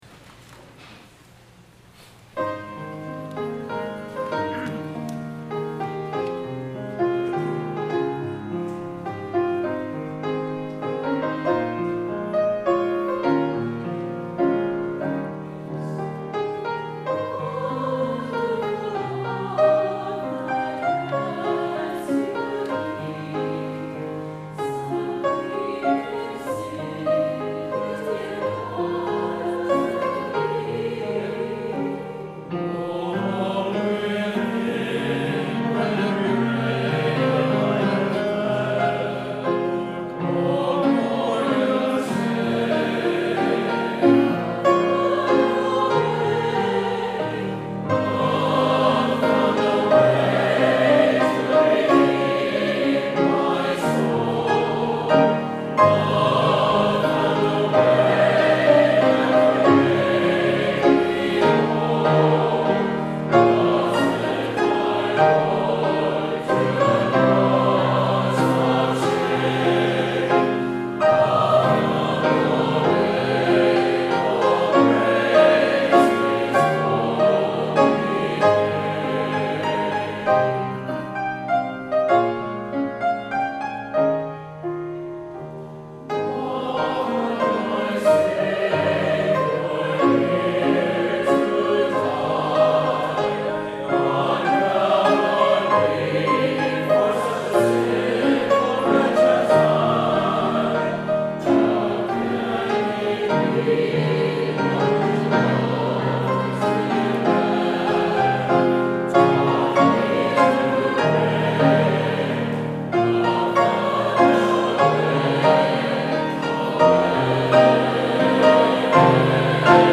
SATB and Piano
Choral
Anthem
Church Choir